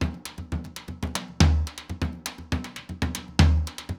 Surdo 1_Salsa 120_2.wav